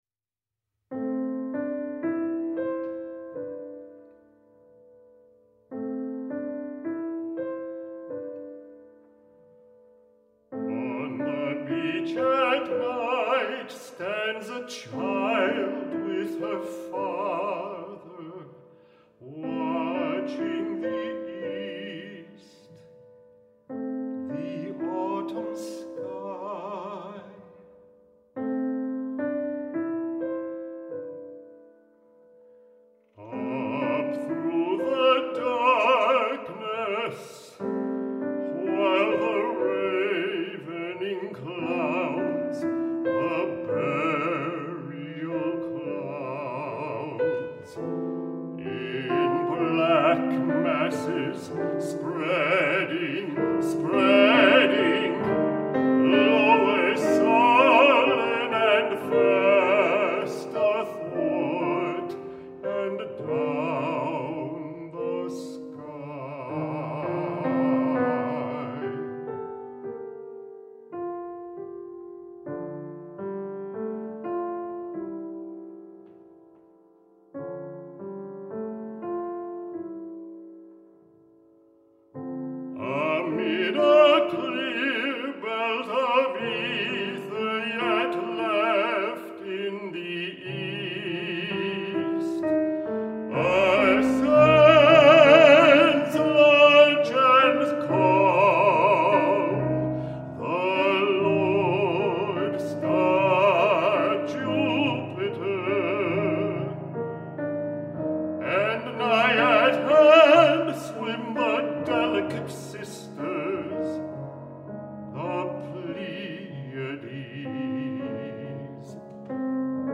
a remarkable baritone singer